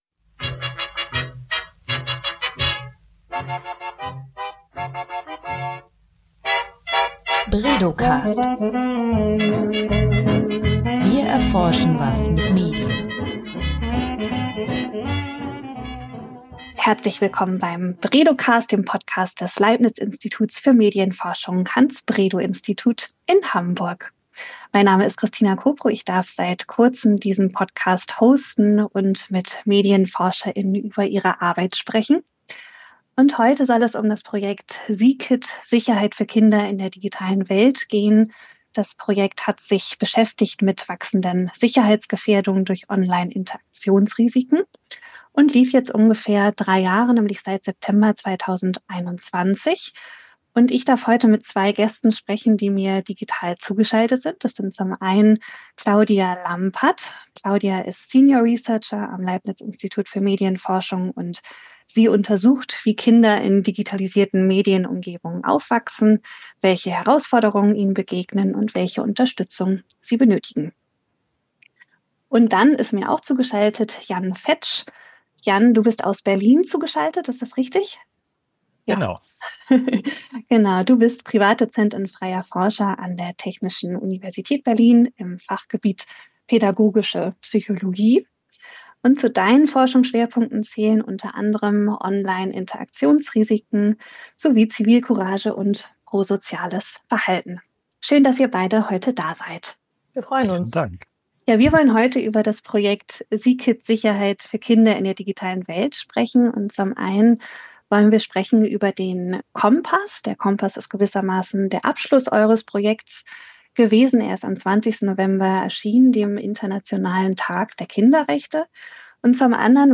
Grund genug, mit zwei Projektbeteiligten über das Projekt und die Teilprojekte zu sprechen.